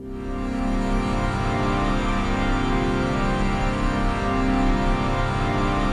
ATMOPAD10 -LR.wav